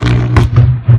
elec_arch2.wav